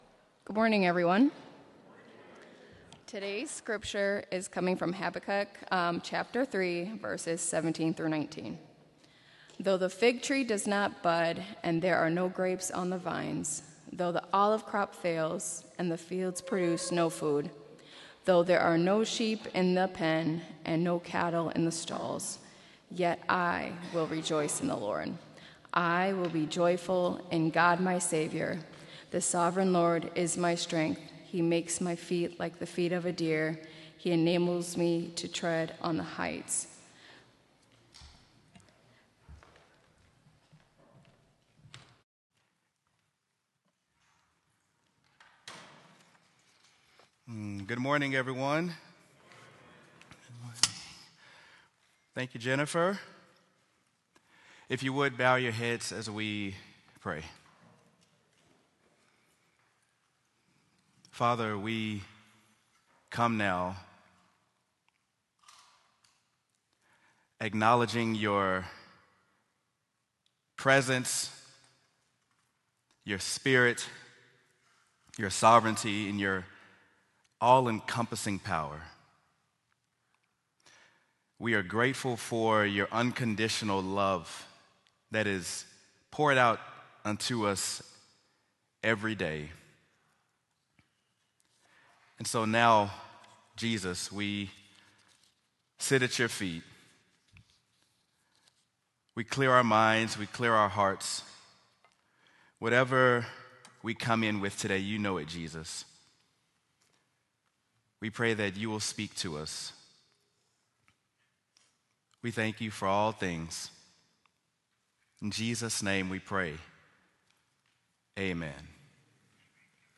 Sermon: Habakkuk: When All Hope Seems Lost
sermon-habakkuk-when-all-hope-seems-lost.m4a